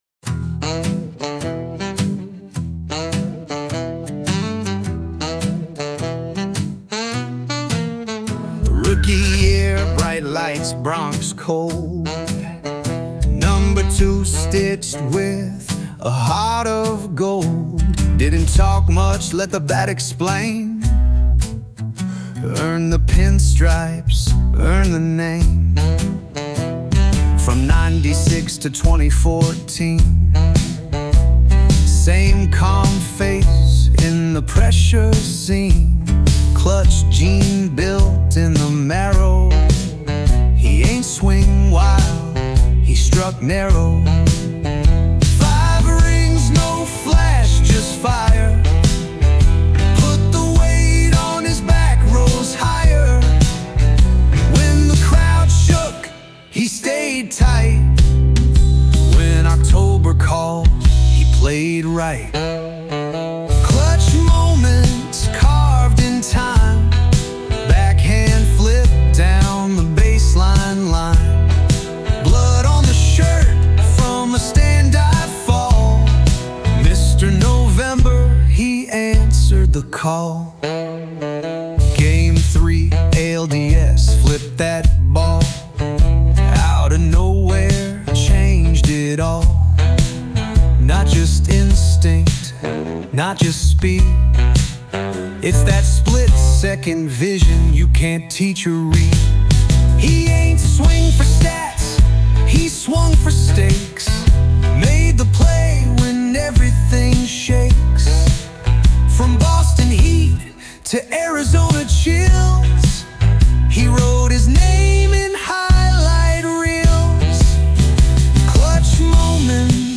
original rap tribute